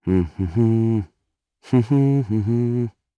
Neraxis-Vox_Hum_jp.wav